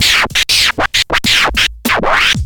scratch344_looped
chop chopping cut cutting dj funky hiphop hook sound effect free sound royalty free Music